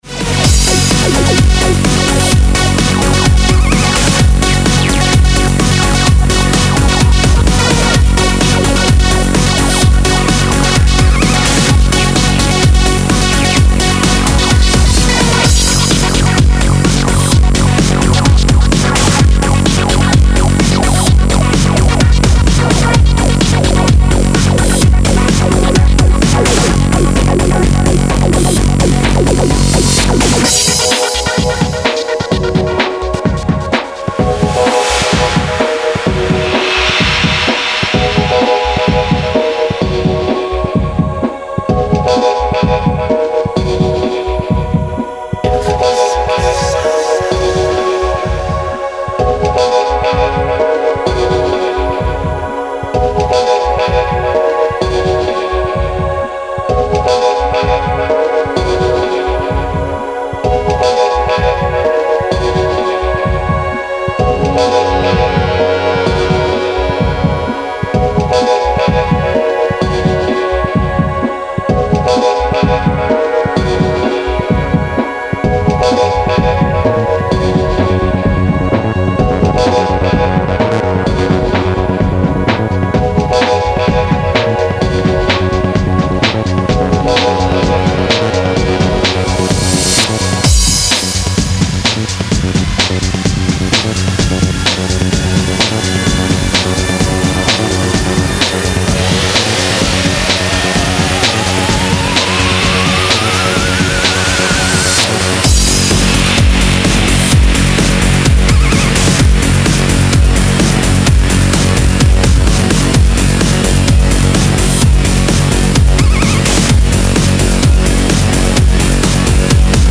Genres Psy-Breaks